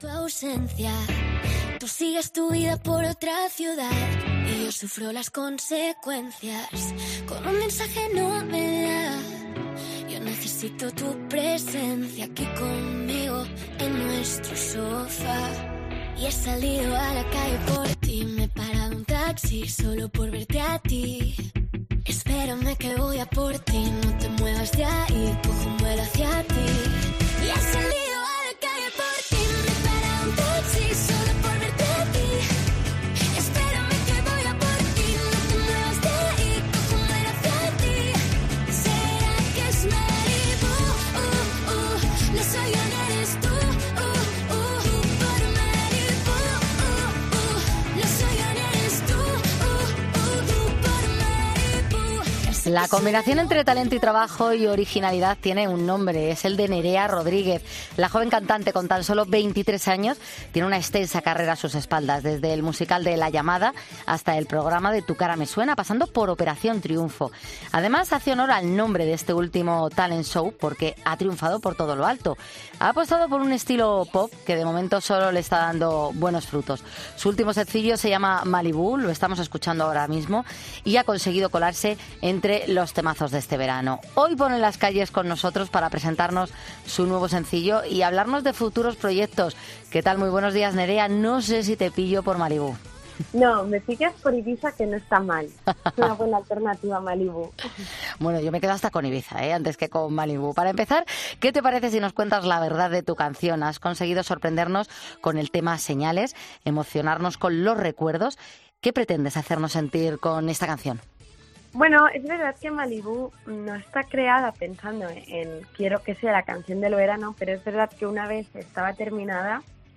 La joven cantante ha pasado por los micrófonos de 'Poniendo las Calles' para contarnos su experiencia durante el rodaje del videoclip de la canción...